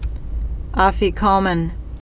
fē-kōmən)